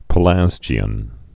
(pə-lăzjē-ən)